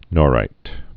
(nôrīt)